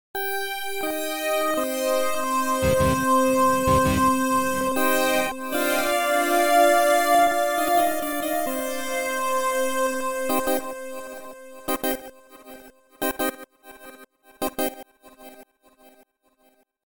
火墙 Contra Bass弦乐 98 BPM
标签： 96 bpm Dancehall Loops Strings Loops 1.65 MB wav Key : Unknown
声道立体声